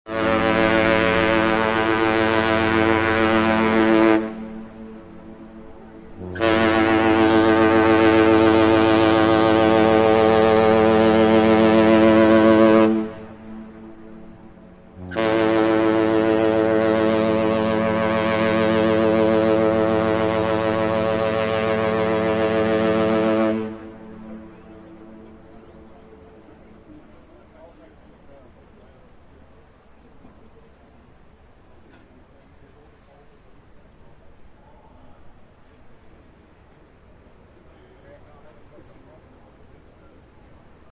Download Ship Horn sound effect for free.
Ship Horn